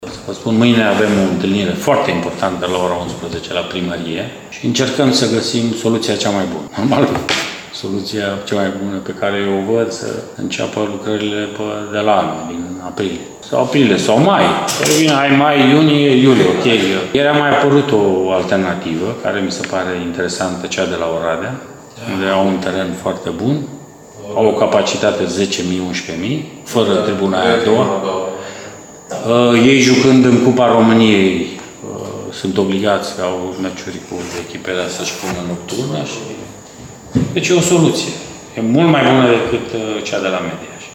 Prezent la conferinţa de presă premergătoare meciului de vineri după-amiază de la Arad, Mircea Rednic şi-a spus părerea vizavi de startul lucrărilor la supraţa de joc de pe arena de pe Mureş şi a adăugat că, dacă acestea vor demara conform planului iniţail, conducerea ia în calcul ca UTA să joace la Oradea şi nu la Mediaş, acolo unde, din câte se pare, starea terenului de joc nu este chiar cea mai bună: